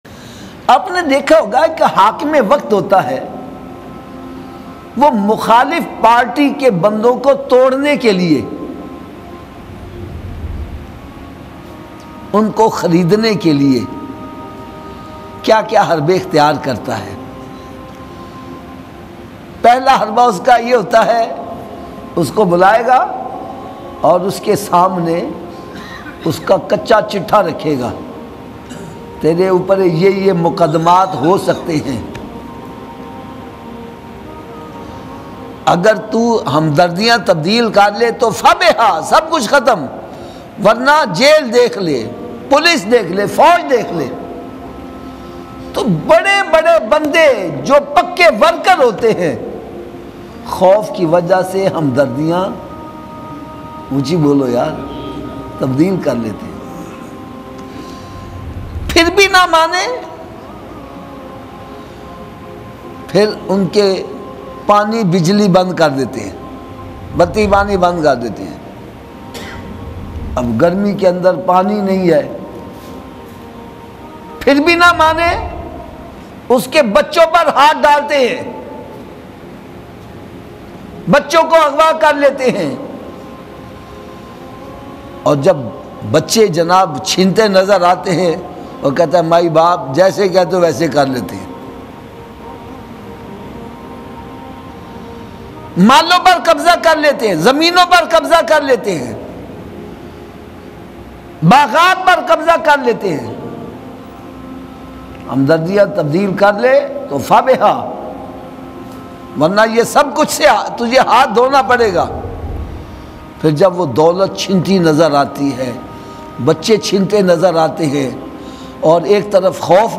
Waqia Karbala Hussain Teri Azmat Ko Salam Bayan MP3